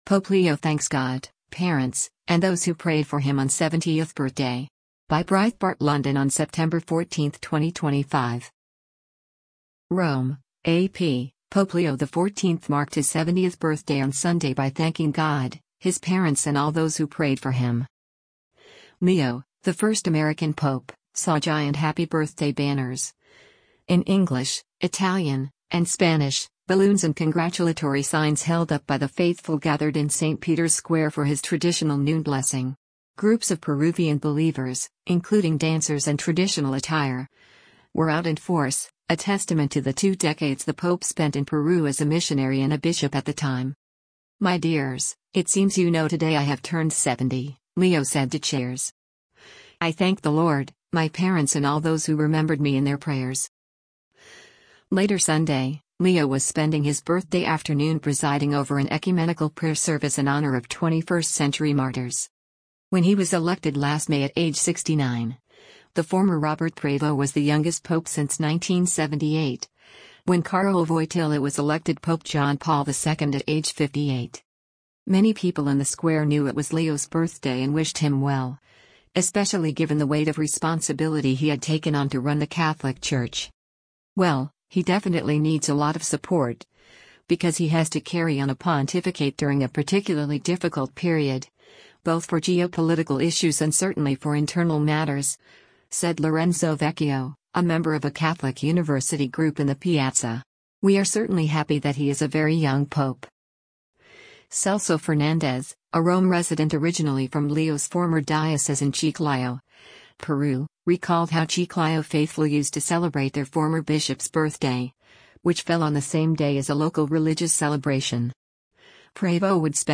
Pope Leo XIV waves to the pilgrims gathered in St.Peter's square during his Sunday Angelus